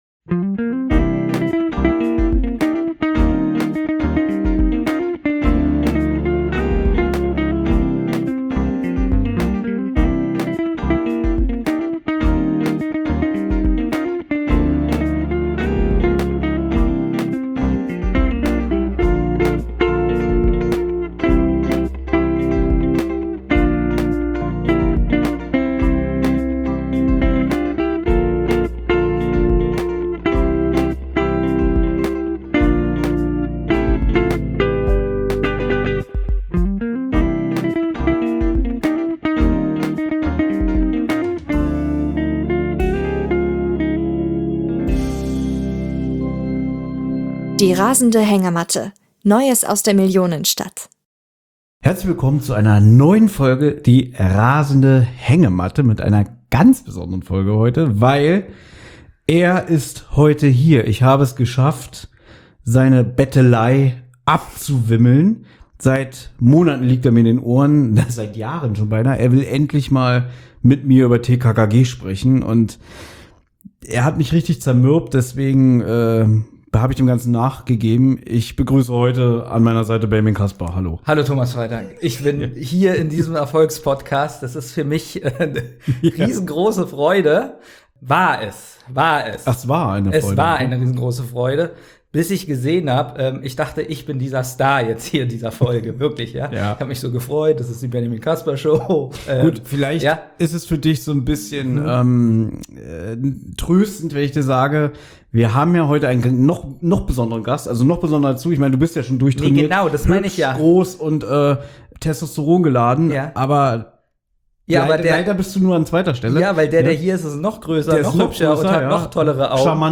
Special: Interview mit Manou Lubowski